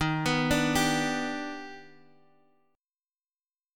G/Eb chord
G-Major-Eb-x,x,1,4,3,3-8.m4a